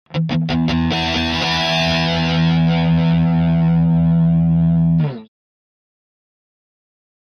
Guitar Heavy Metal Finale Chord 4 - Long